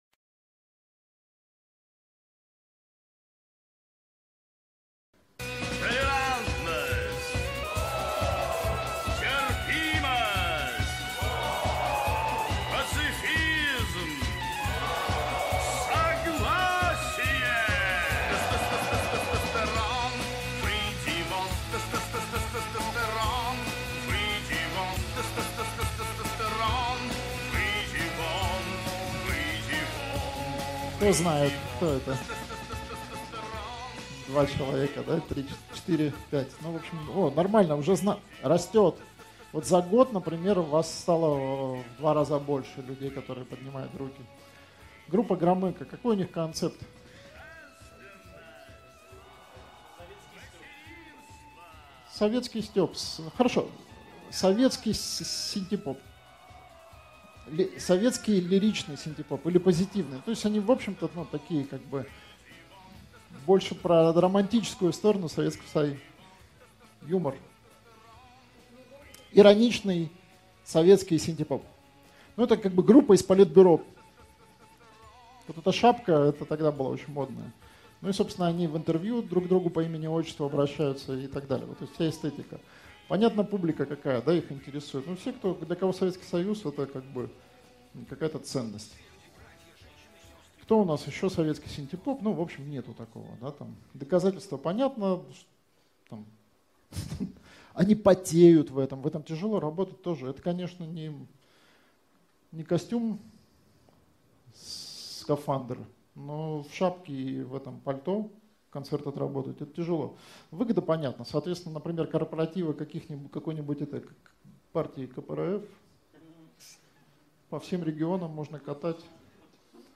Аудиокнига Стратегия позиционирования артиста ч.3 Ответы на вопросы | Библиотека аудиокниг